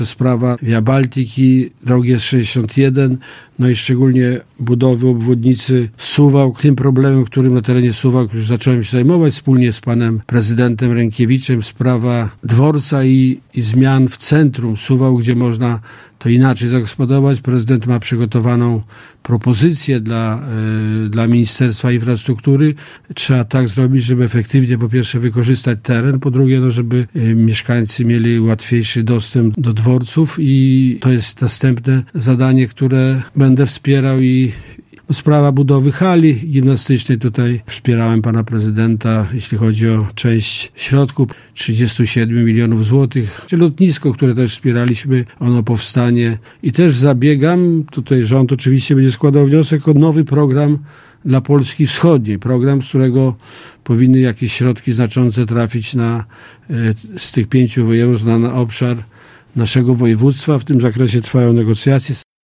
– Podlaskie i Warmińsko-Mazurskie potrzebują więcej dróg, połączeń kolejowych, wsparcia przedsiębiorców i ochrony rolników – mówił w poniedziałek (29.04) na antenie Radia 5 Krzysztof Jurgiel.